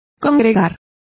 Complete with pronunciation of the translation of congregating.